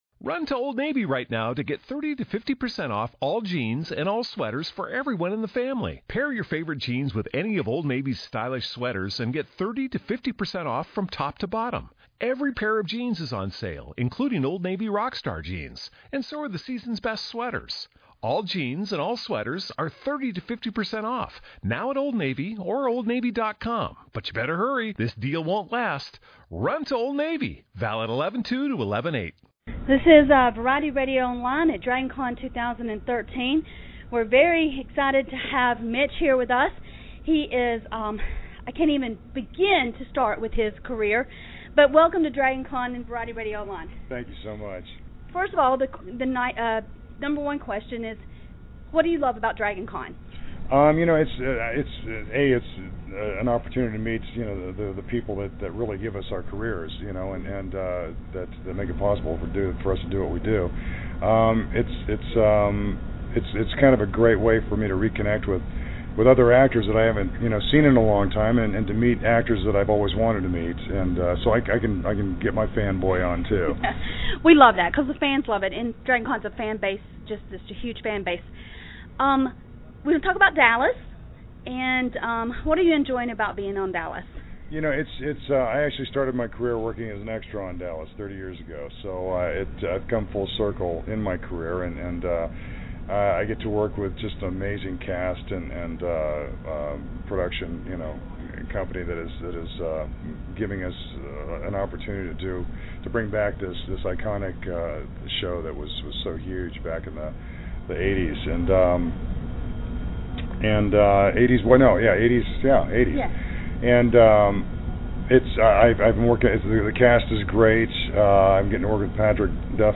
Mitch Pileggi Interview
Mitch took a few minutes at Dragon Con 2013 to sit down with us and talk about his role as Harris Ryland on TNT hit series Dallas.